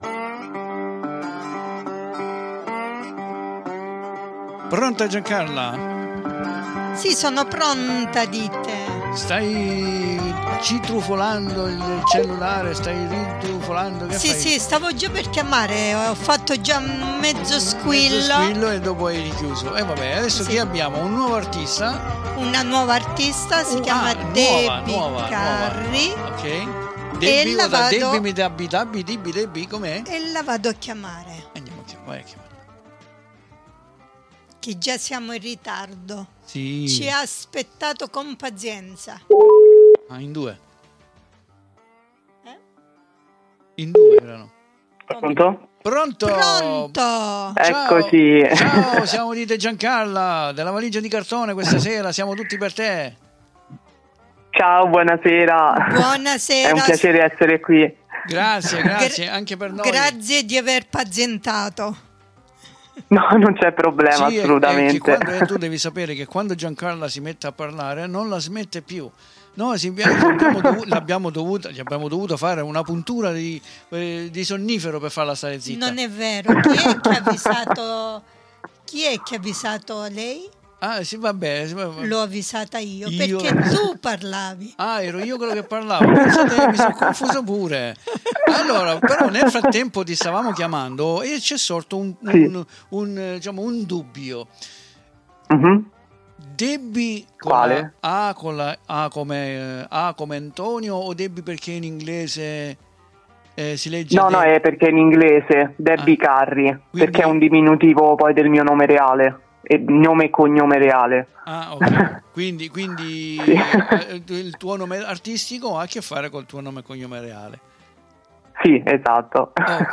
CI RISPONDE CON UN BEL VOCIONE , CARICO E BELLO!